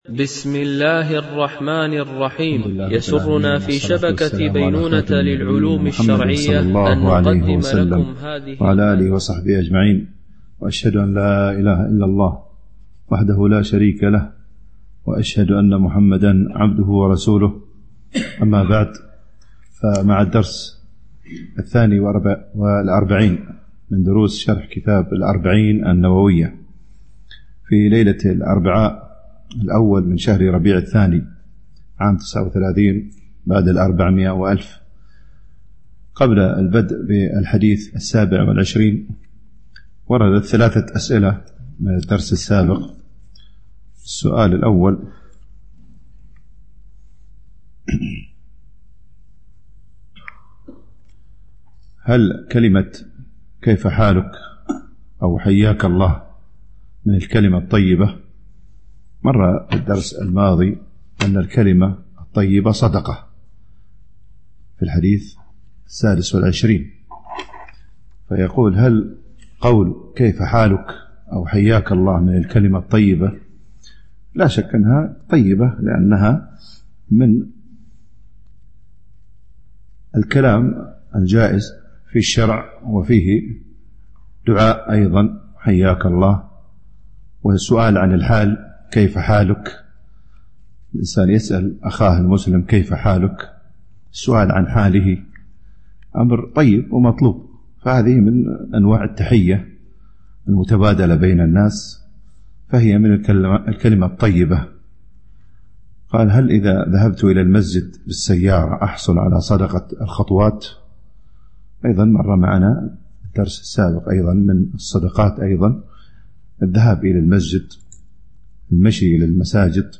شرح الأربعين النووية ـ الدرس 42 (الحديث 27)